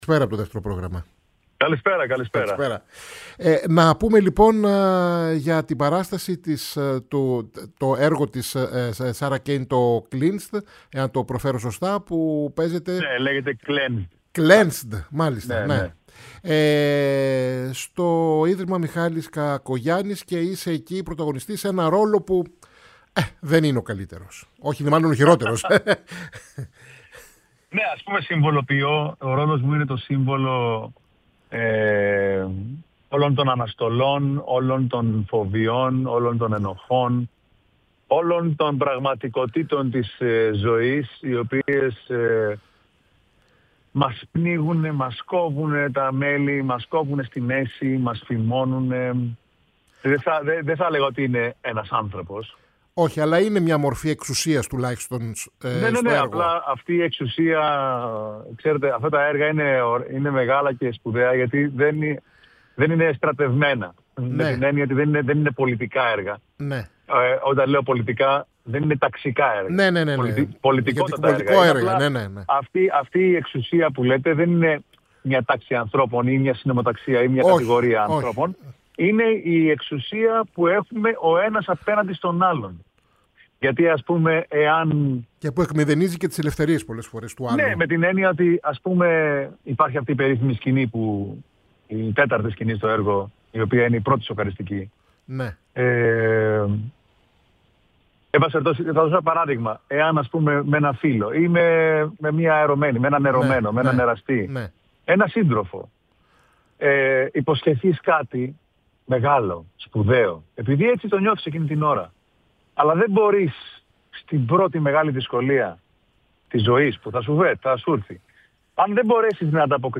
Ο Χρήστος Λούλης ήταν ο σημερινός καλεσμένος